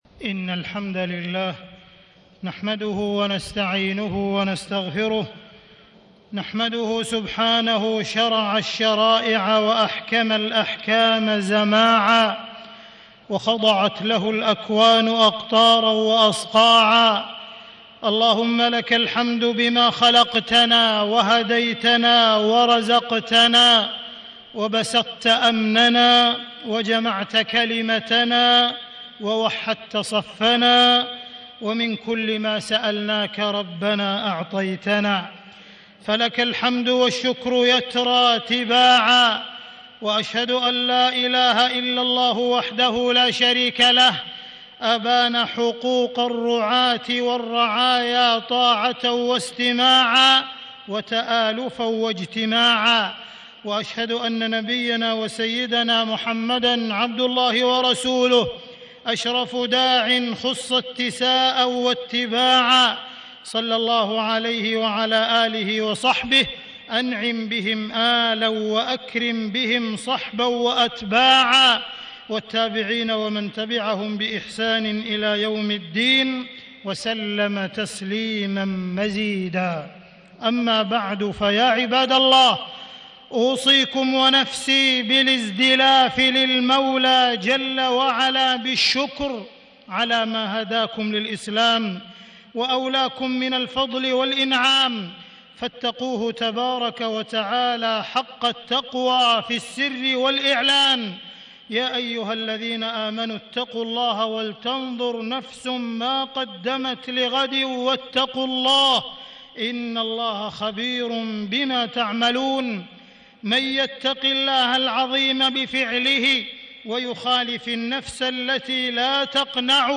تاريخ النشر ٢٤ ربيع الثاني ١٤٣٦ هـ المكان: المسجد الحرام الشيخ: معالي الشيخ أ.د. عبدالرحمن بن عبدالعزيز السديس معالي الشيخ أ.د. عبدالرحمن بن عبدالعزيز السديس أحكام الولاية والإمامة